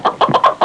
Chicken Sound Effect
Download a high-quality chicken sound effect.
chicken-1.mp3